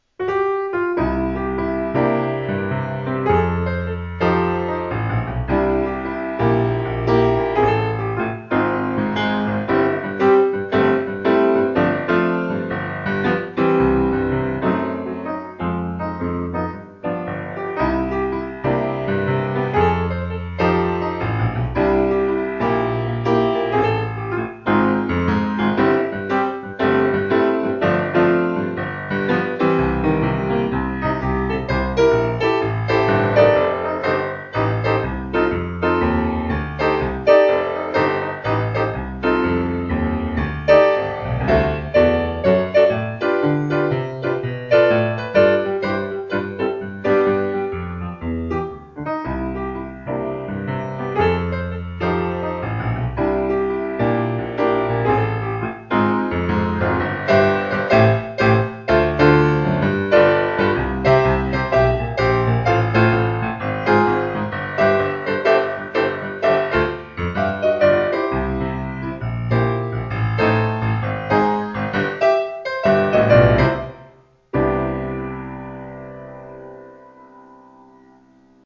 jazz piano